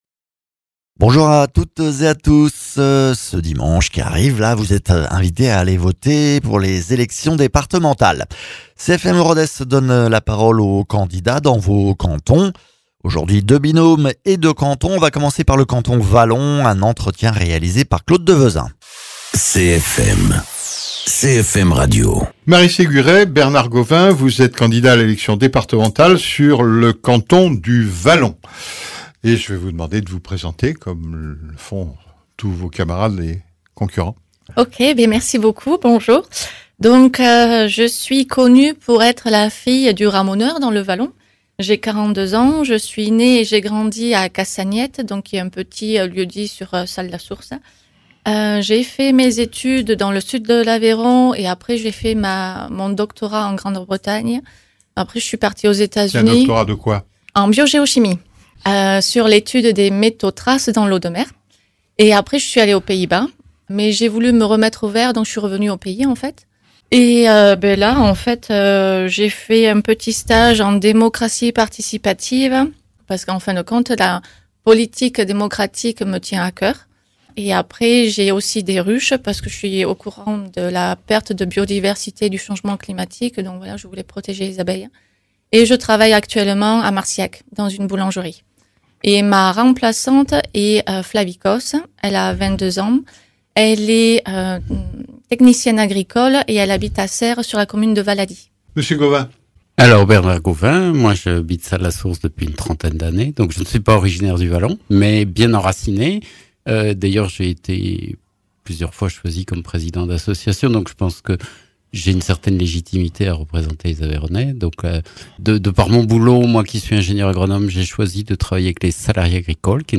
Dans la cadre des élections départementales des 20 et 27 juin, CFM Rodez invite les candidats des 6 cantons de son bassin de diffusion.